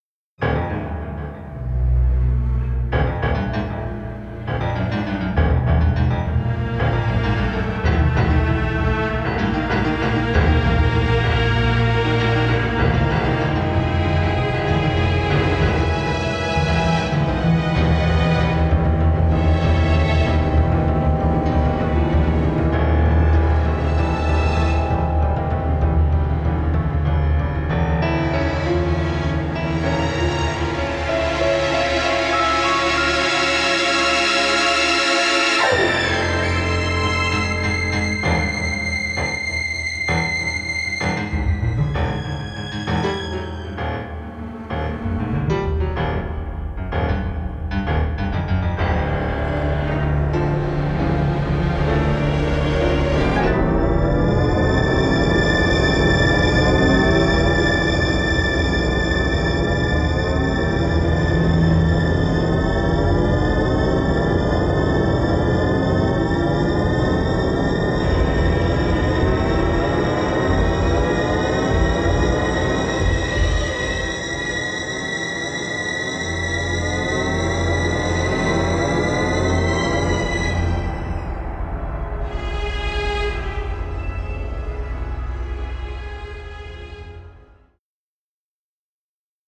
ELECTRONIC CUES